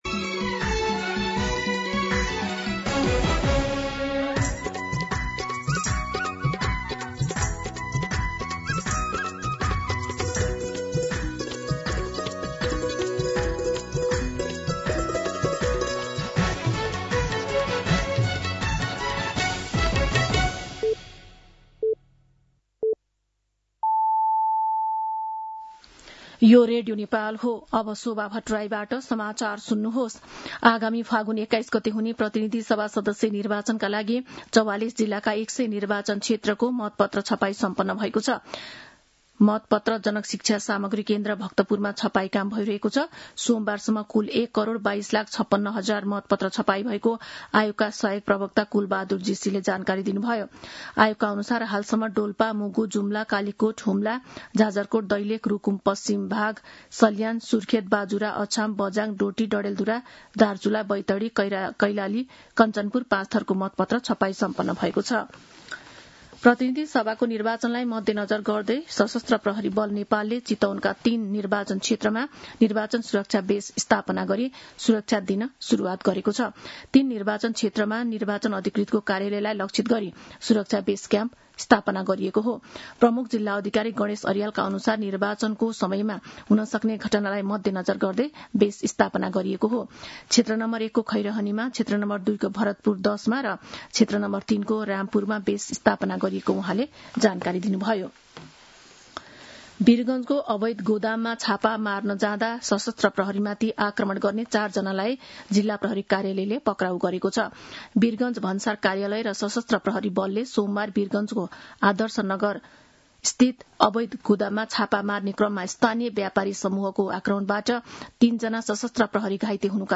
An online outlet of Nepal's national radio broadcaster
मध्यान्ह १२ बजेको नेपाली समाचार : २७ माघ , २०८२